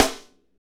SNR FNK S06L.wav